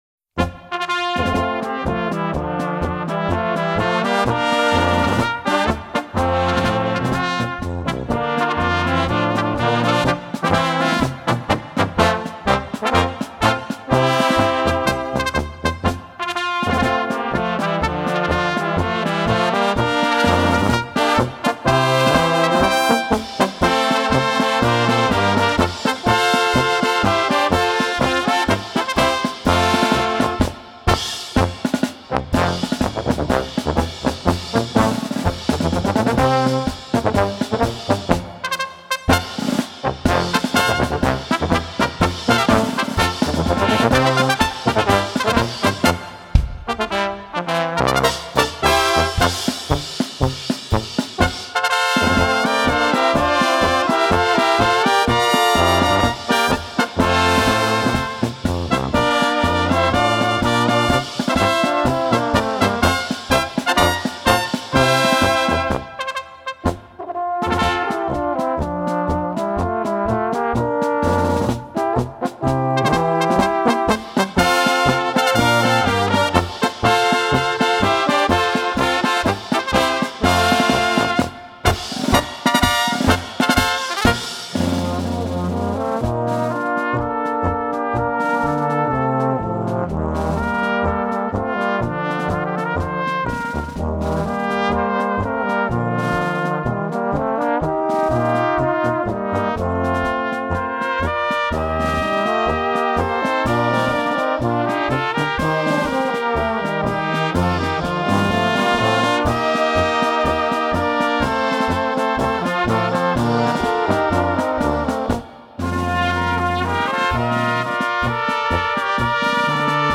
Gattung: Polka für Böhmische Besetzung
Besetzung: Kleine Blasmusik-Besetzung
ist eine melodiöse Polka
1.Flügelhorn B
2.Flügelhorn B
Trompete B
Tenorhorn B
Bariton B/C
Tuba B/C
Schlagzeug